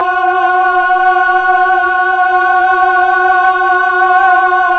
Index of /90_sSampleCDs/Roland LCDP09 Keys of the 60s and 70s 1/VOX_Melotron Vox/VOX_Tron Choir